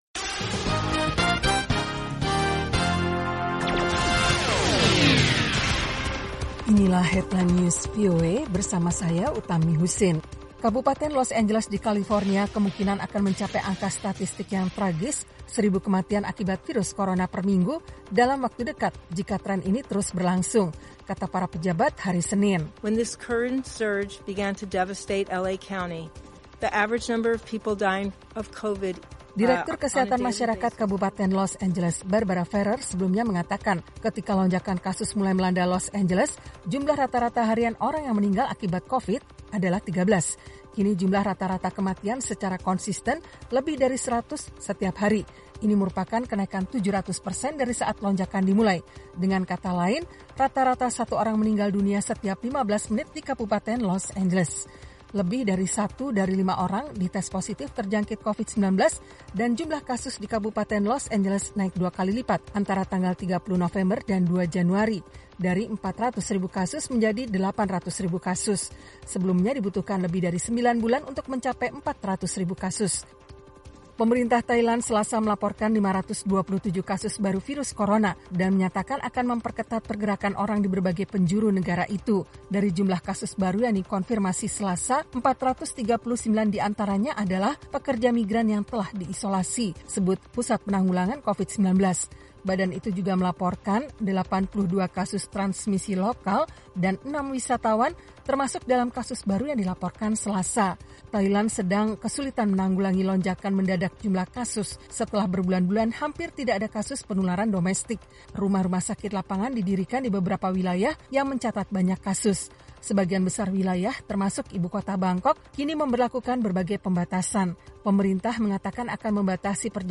Simak berita terkini langsung dari Washington dalam Headline News, bersama para penyiar VOA, menghadirkan perkembangan terakhir berita-berita internasional.